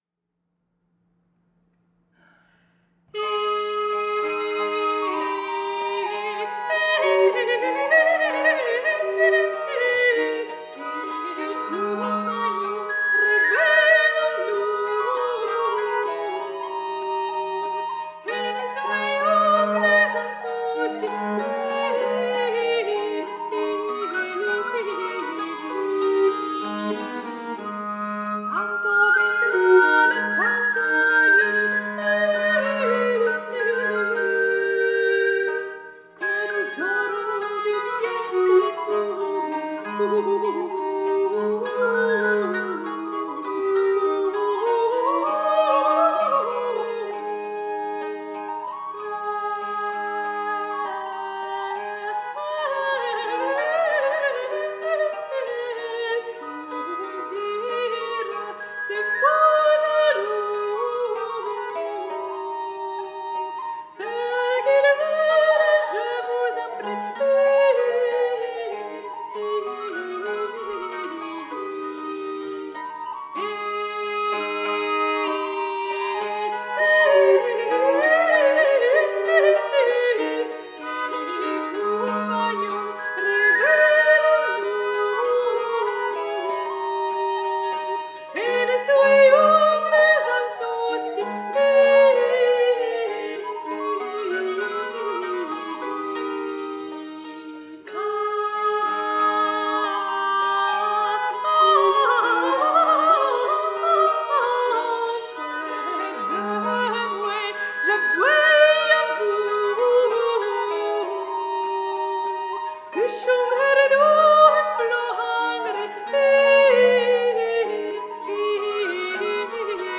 ENSEMBLE BALLATA | MUSIQUES MÉDIÉVALES
luth
LES FLÛTES À BEC
LA VIÈLE À ARCHET